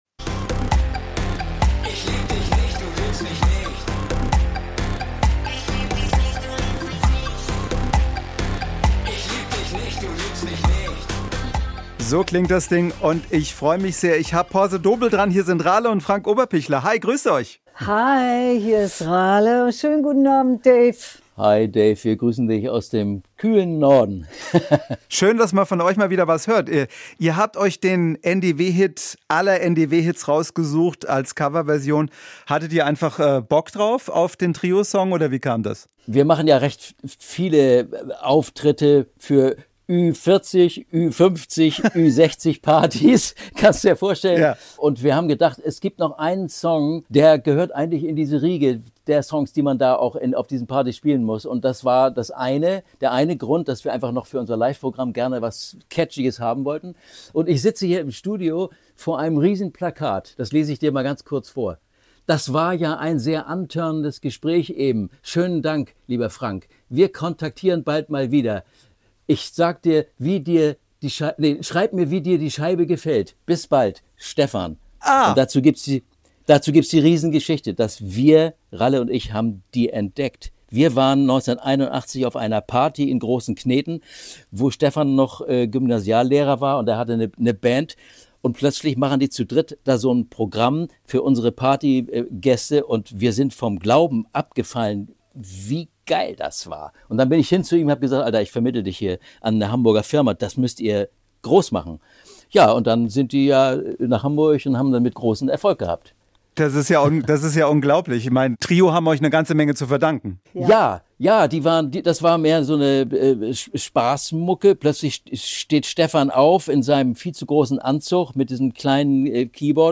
SWR1 Musikinterview